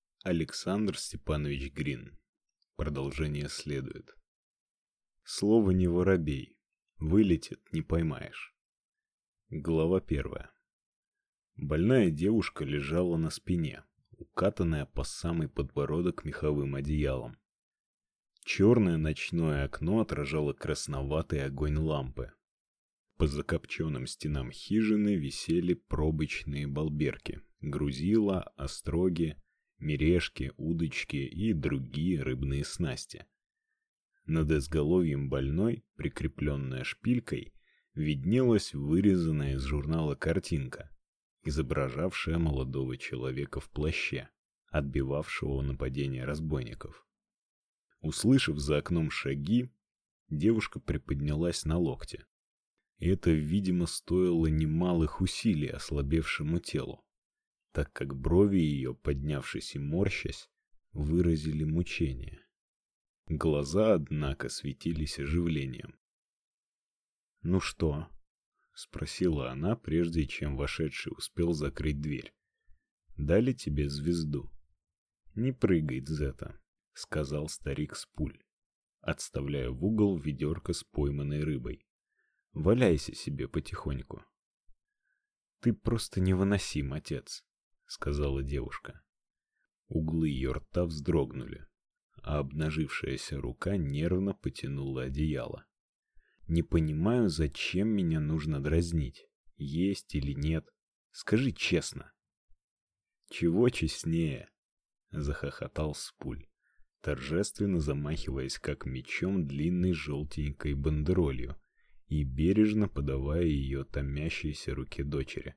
Аудиокнига Продолжение следует | Библиотека аудиокниг